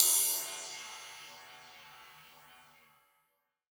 DnBCymbalA-05.wav